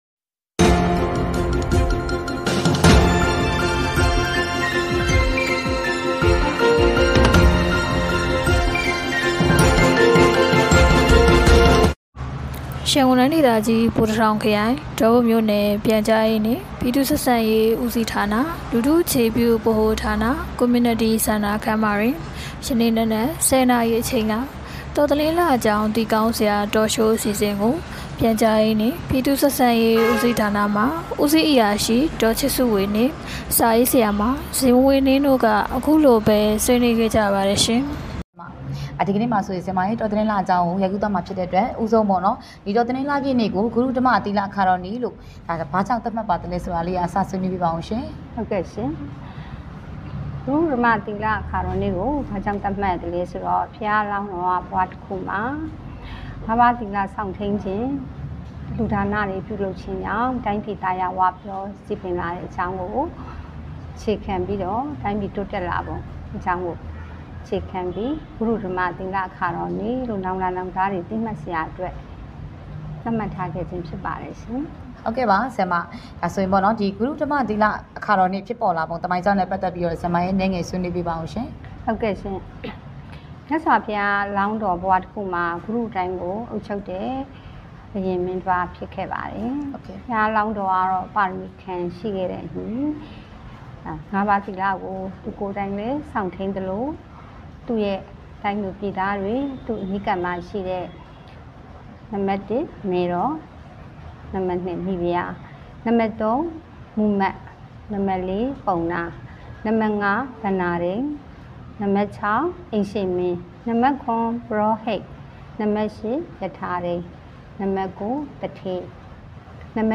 ဒေါပုံမြို့နယ်၌ တော်သလင်းလအကြောင်းသိကောင်းစရာ အသိပညာပေး Talk Showပြုလု...